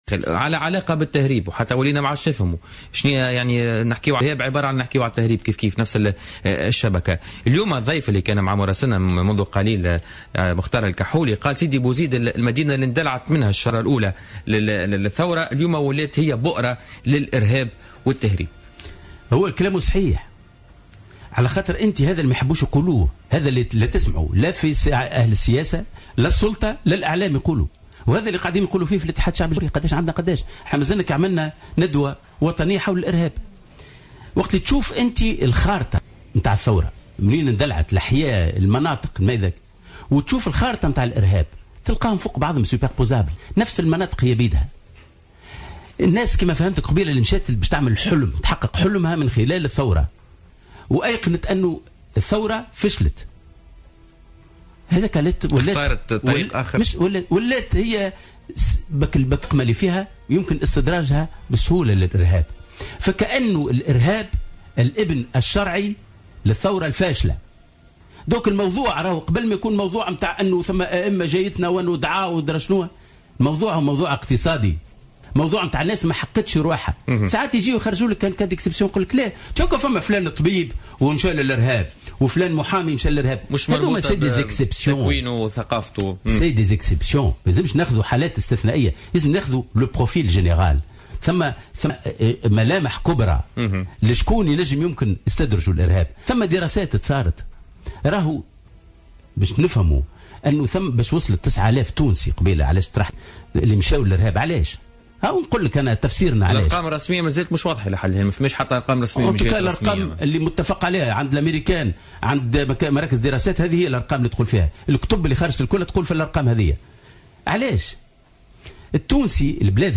قال أمين عام حزب الاتحاد الشعبي الجمهوري لطفي المرايحي وضيف برنامج بوليتكا لليوم الخميس 17 ديسمبر 2015 إن الإرهاب هو الابن الشرعي للثورة "الفاشلة" على حد تعبيره.